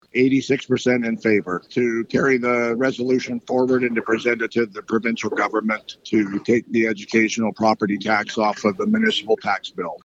Rocky Mountain House Mayor Shane Boniface speaking about a Nov. 13 resolution brought to the ABMunis fall conference in Calgary